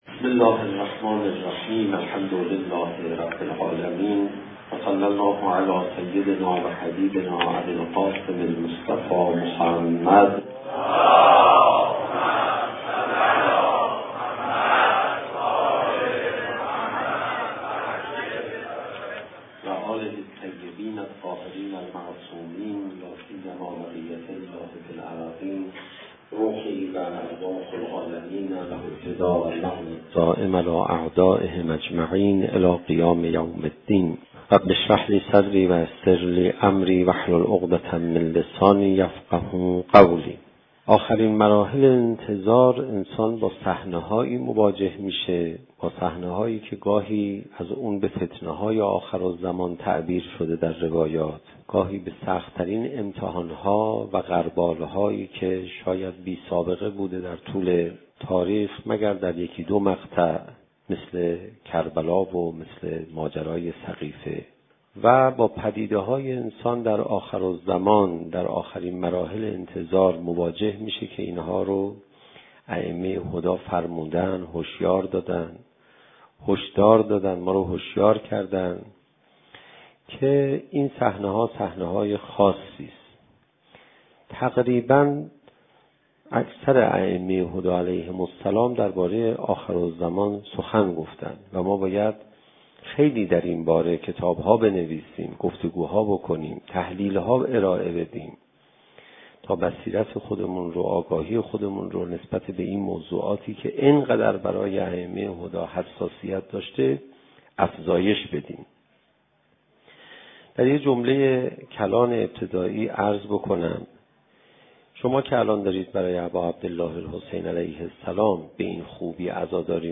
زمان: 01:06:38 | حجم: 15.5 MB | تاریخ: 1395 | مکان: حسینیة آیت الله حق شناس